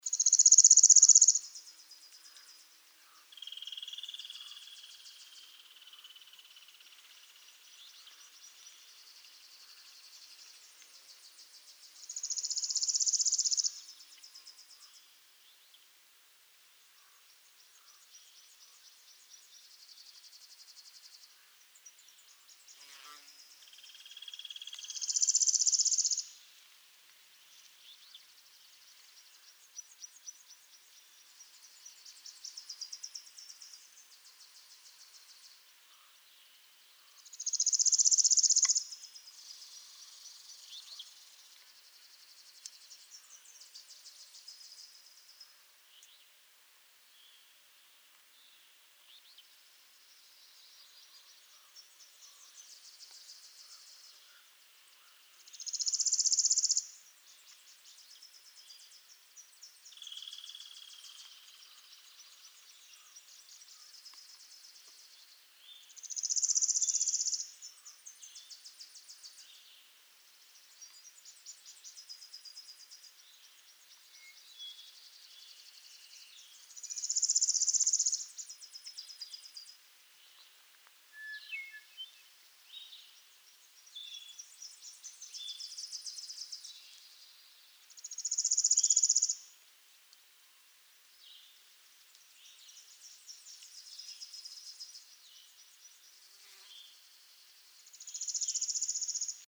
Palm Warbler
Slow, clear song.